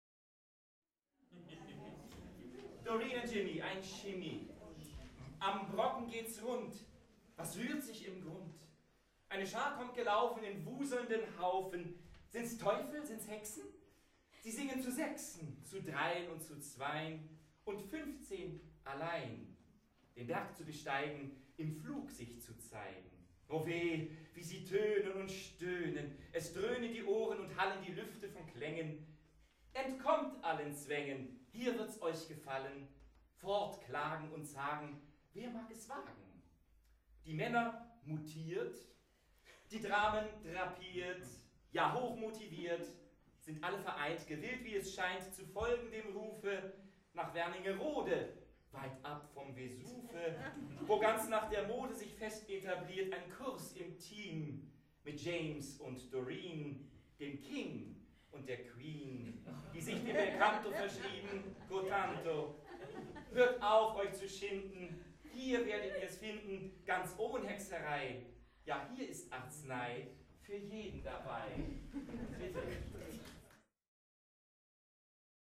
MP3-Aufnahmen vom Abschlusskonzert VI. Meisterkurs 2017 ...
Text & Rezitation